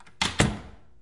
橱柜，门，按钮 1 " 木质关门锁扣
描述：木门关闭与闩锁
Tag: 门闩锁 木锁存器 锁存器 关门 Woode门 闩锁关闭